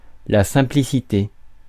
Ääntäminen
Ääntäminen France: IPA: [la sɛ̃.pli.si.te] Tuntematon aksentti: IPA: /sɛ̃.pli.si.te/ Haettu sana löytyi näillä lähdekielillä: ranska Käännös Ääninäyte Substantiivit 1. simplicity US 2. simpleness 3. funkiness 4. easiness Suku: f .